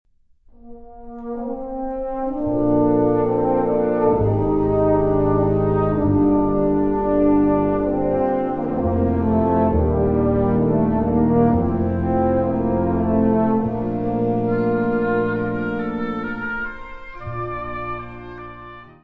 Kategorie Blasorchester/HaFaBra
Besetzung Ha (Blasorchester)